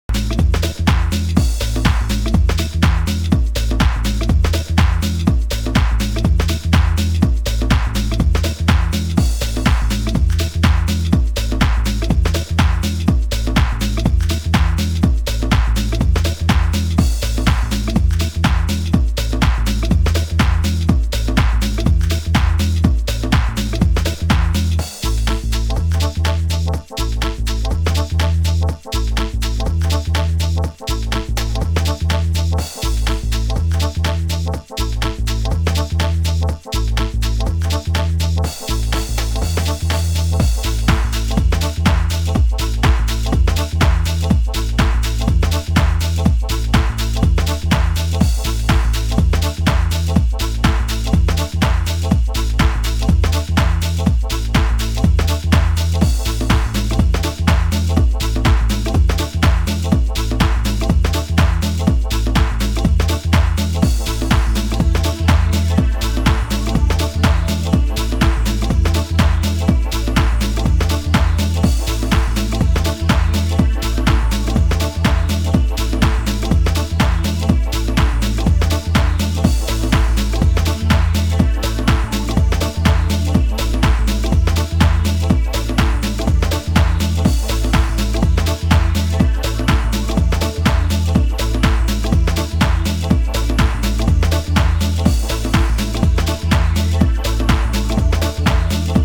(Dub) 　C2